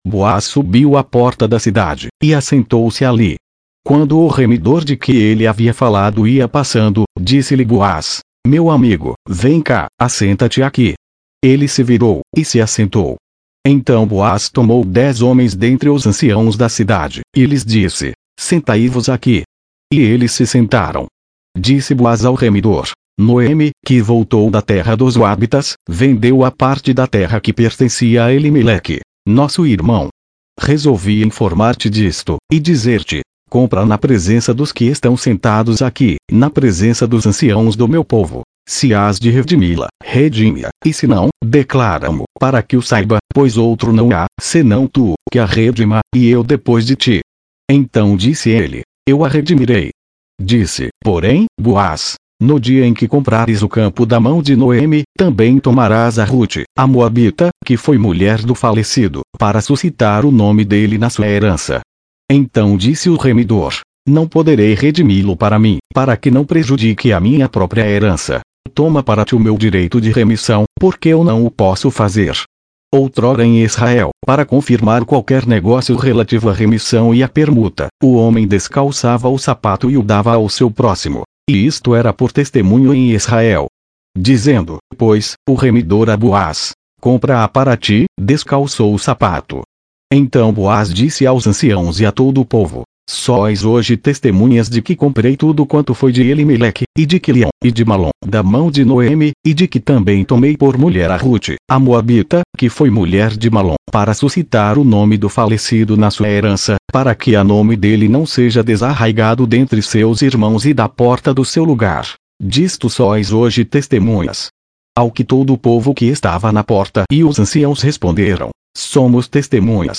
Leitura na versão Revisada - Portugués